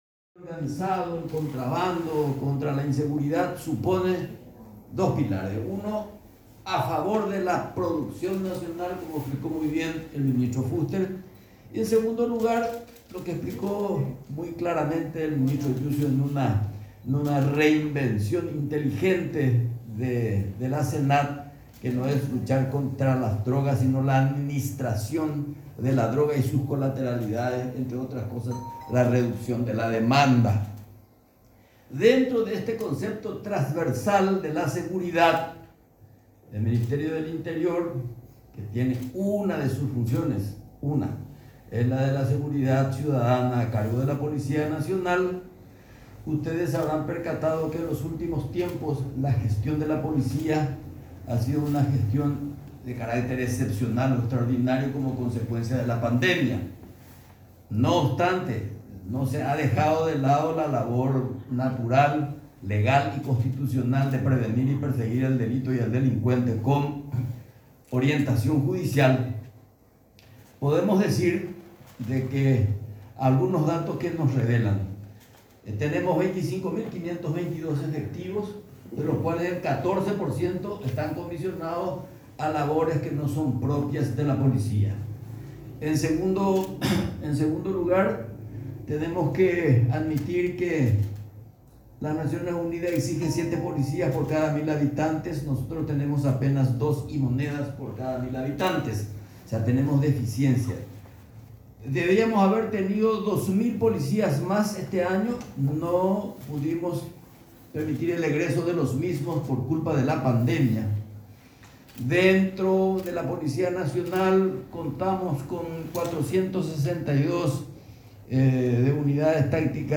“Lo que se va a hacer es reforzar y redoblar la presencia policial en centros estratégicos de distracción y ocio en combinación con el Ministerio Público, o sea que nuestra vigilancia va a ser igual que al comienzo de la pandemia”, dijo Acevedo en conferencia de prensa.
22-CONFERENCIA-EUCLIDES-PARTE-1.mp3